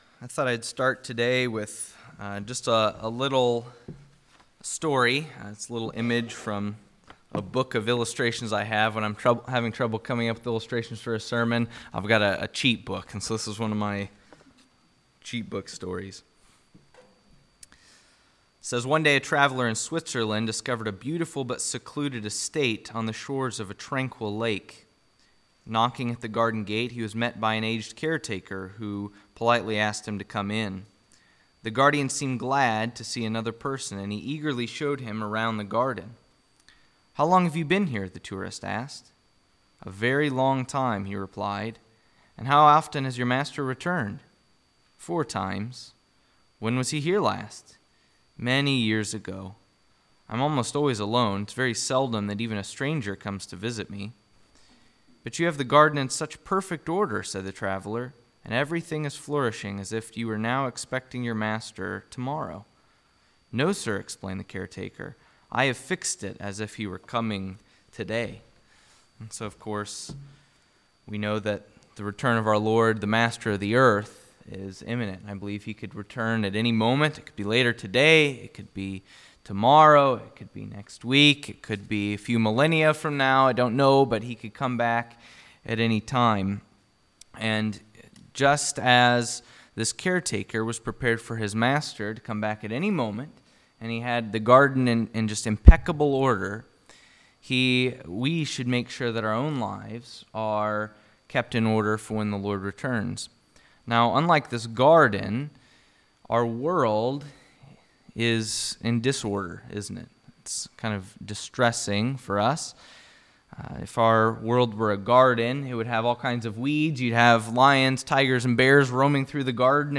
Rossville Bible Fellowship Sermons
October-24th-Sermon.mp3